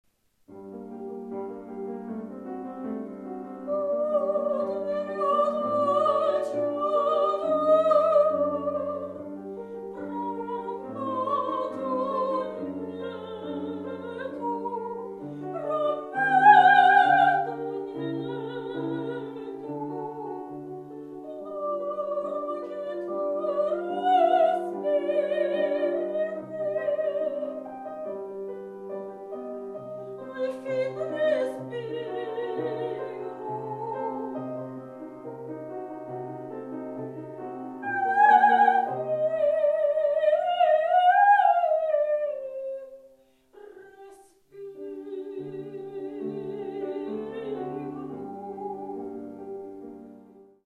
Ich bin Sopranistin und interpretiere sehr gern Klassische Musik, angefangen von Liedern und Arien alter italienischer Meister und Werken von J.S.Bach, G.F.Händel, über Kompositionen von W.A.Mozart, J.Haydn, F.Schubert, J.Brahms, G. Puccini, G.Verdi bis hin zu G.Gershwin - um nur einige Beispiele zu nennen.